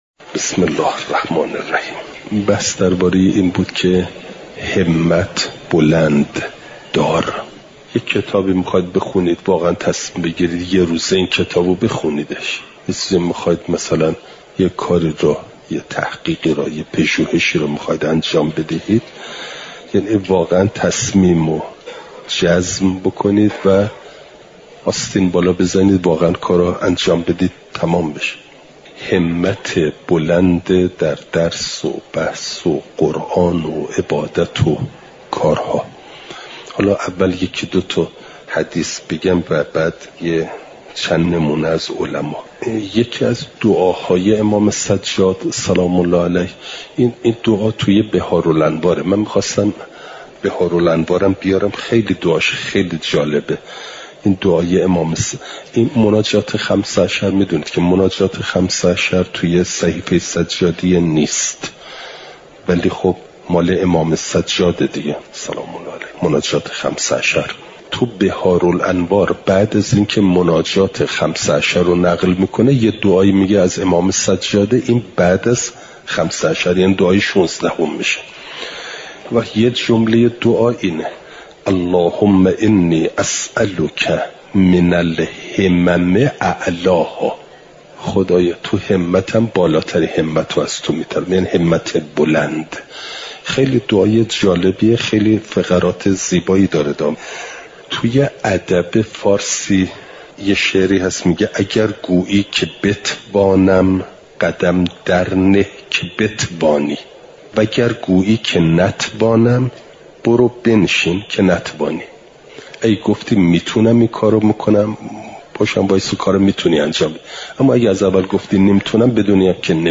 بیانات اخلاقی
چهارشنبه ۷ آذرماه ۱۴۰۳، حرم مطهر حضرت معصومه سلام ﷲ علیها